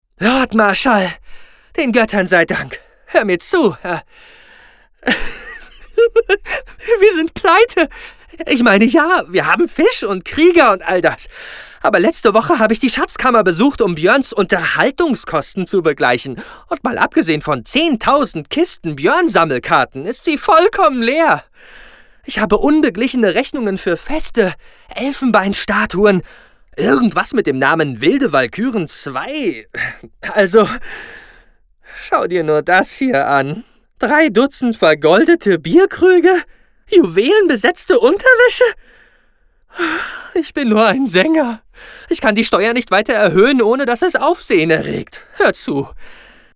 Synchronsprecher, Hörspieler, Off-Stimme, Stationvoice
Sprechprobe: Sonstiges (Muttersprache):
Young, versatile, dynamic and upbeat. Located in Germany with own ISDN studio.